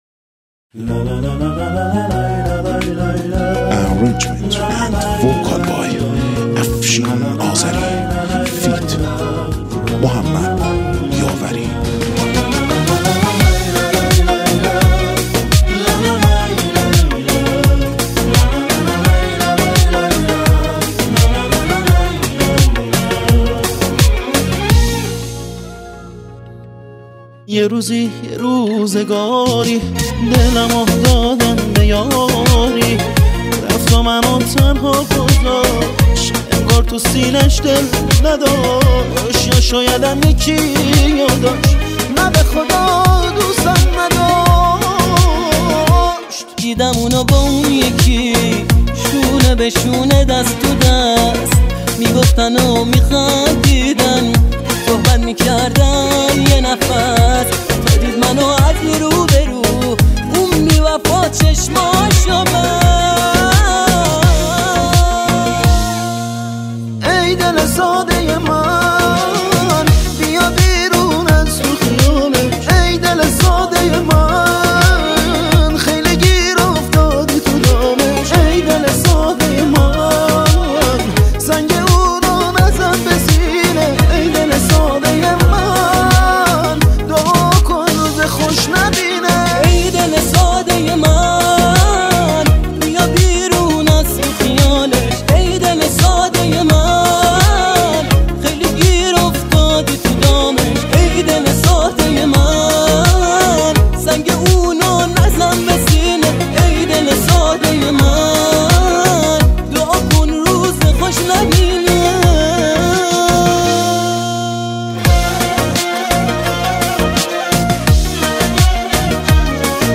ارکستر زهی
گیتار کلاسیک
باغلاما